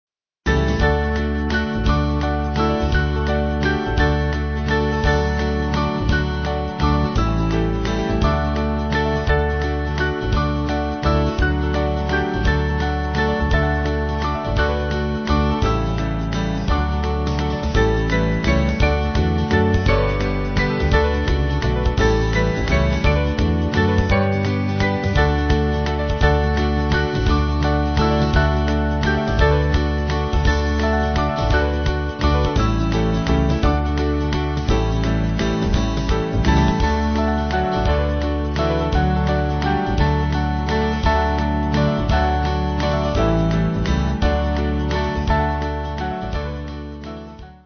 Swing Band
(CM)   4/D-Eb